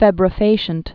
(fĕbrə-fāshənt)